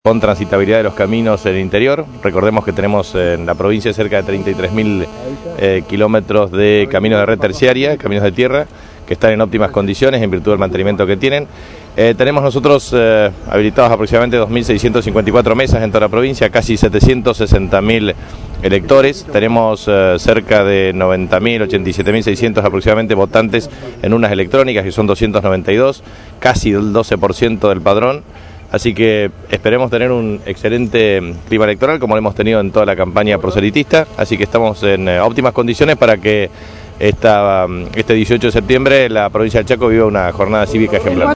«Estamos en óptimas condiciones para que este 18  de septiembre la provincia de Chaco viva una jornada cívica ejemplar», lo dijo el gobernador Jorge Capitanich luego de emitir su voto.
18-09-11 Jorge Capitanich 01 Esperamos tener una jornada ejemplar